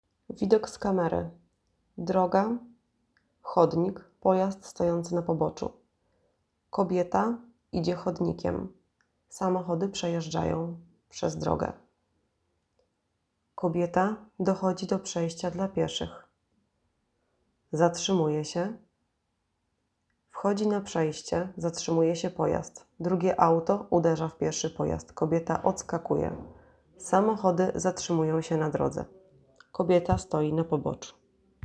Nagranie audio audiodeskrycja filmu.m4a